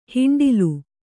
♪ hiṇḍilu